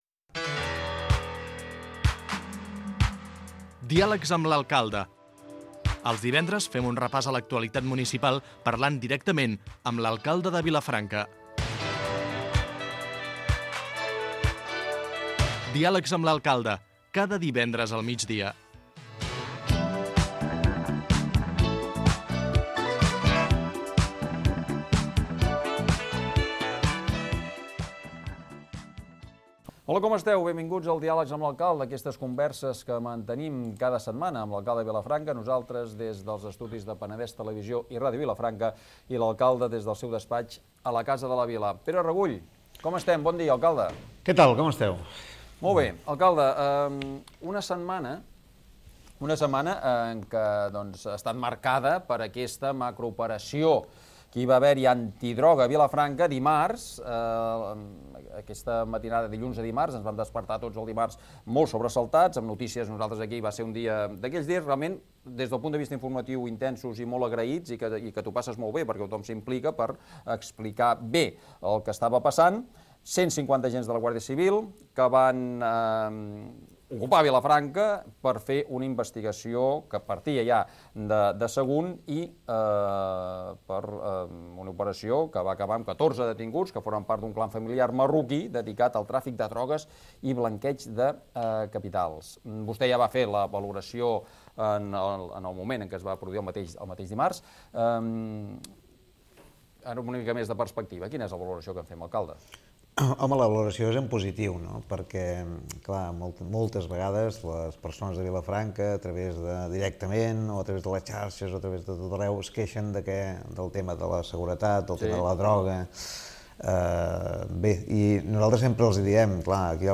Pere Regull, entrevistat